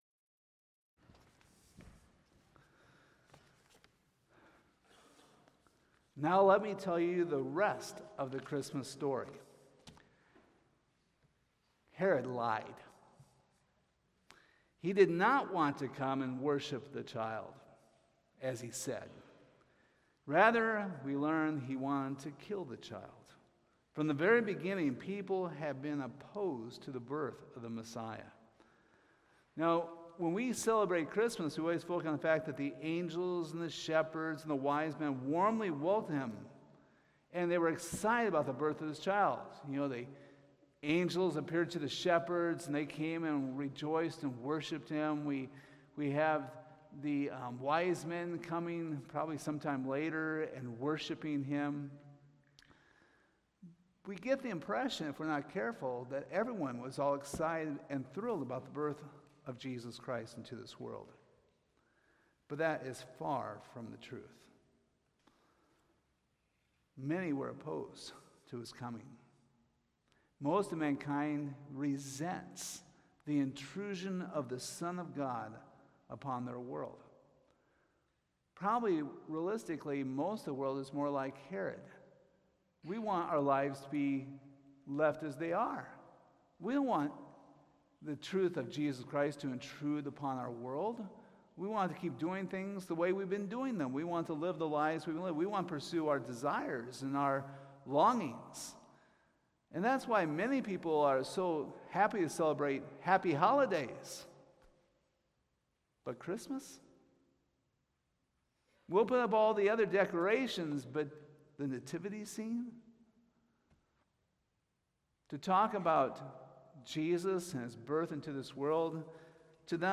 Matthew 2:13-23 Service Type: Sunday Morning How significant is knowing the exact date of Christ's birth?